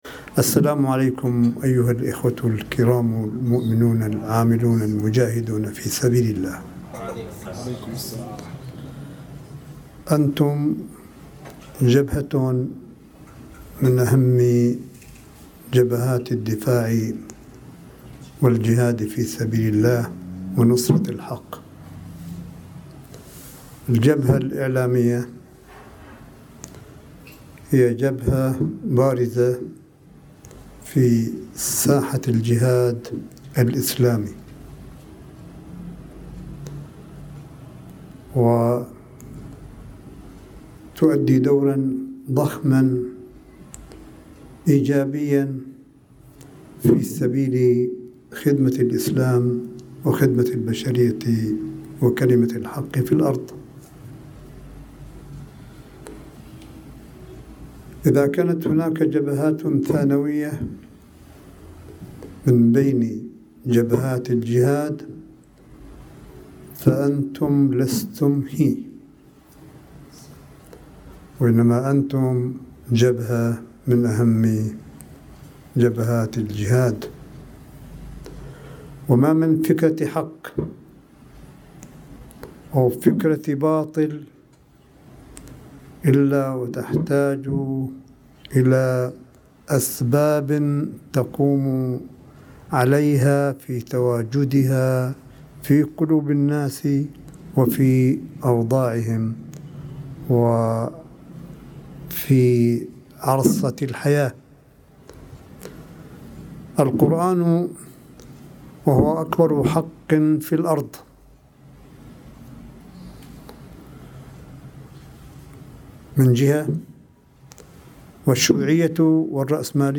ملف صوتي لكلمة سماحة آية الله الشيخ عيسى أحمد قاسم لعدد من الإعلاميين الناشطين في وسائل الإعلام الإيرانية – وذلك بمقر إقامته في مدينة قم المقدسة 07 أغسطس 2019